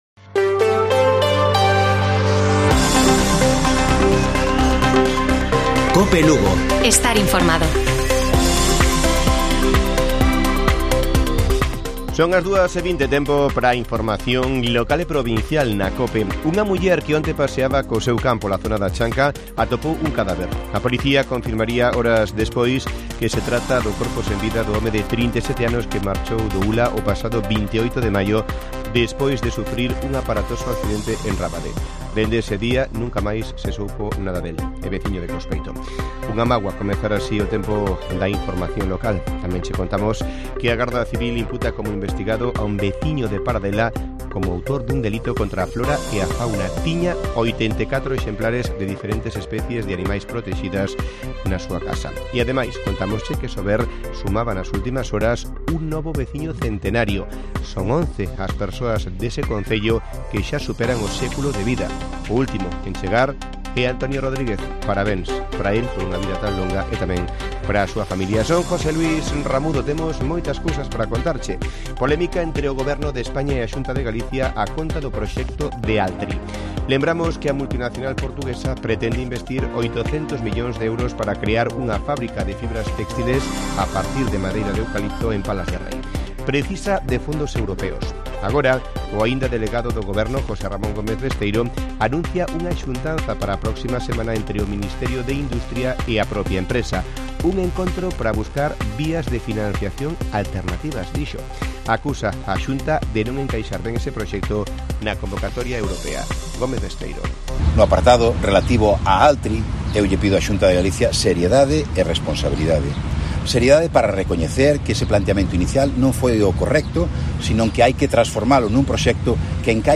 Informativo Mediodía de Cope Lugo. 9 de junio. 14:20 horas